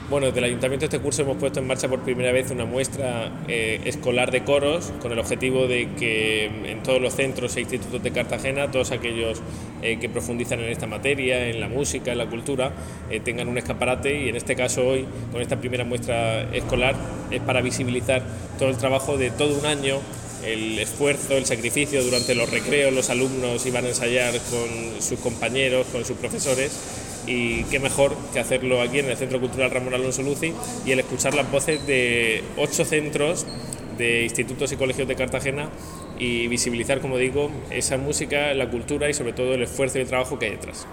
Más de 300 alumnos participan en la primera muestra de coros escolares en el Luzzy